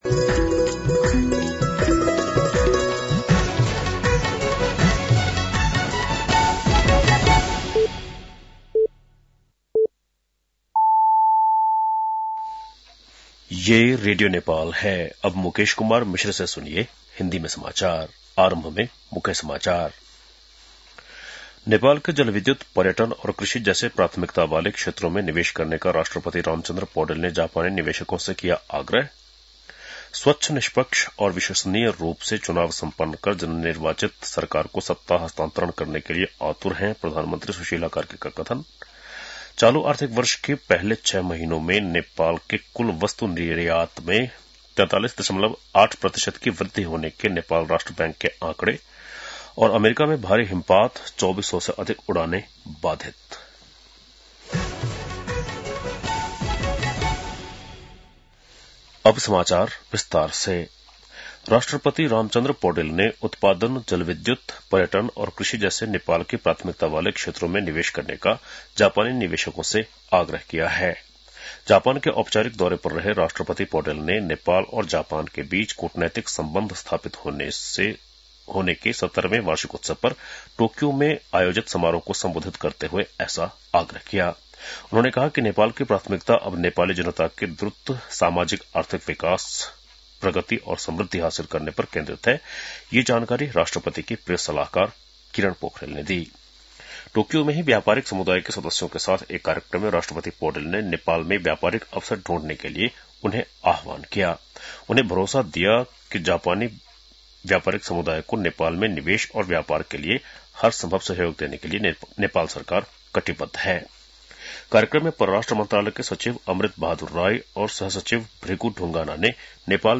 बेलुकी १० बजेको हिन्दी समाचार : १९ माघ , २०८२